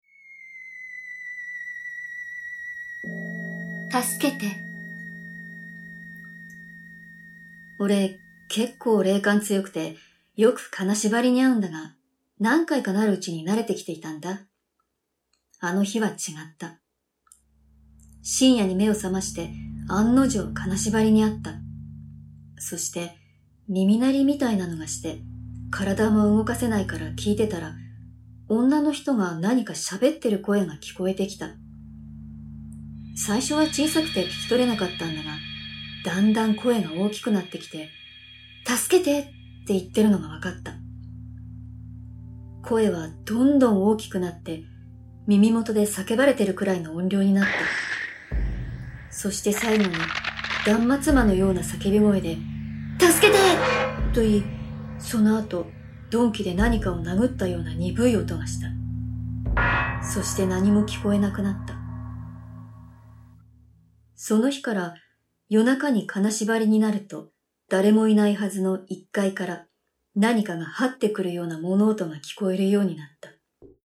[オーディオブック] ネットであった本当に怖い実話 3D Vol.2-5〜破〜 (下)
「タスケテ」他、全5話27分に及ぶ怪談を収録。SEにもこだわり、最先端技術を駆使し、擬似的に3D音響空間を再現、格別の臨場感を体感出来ます！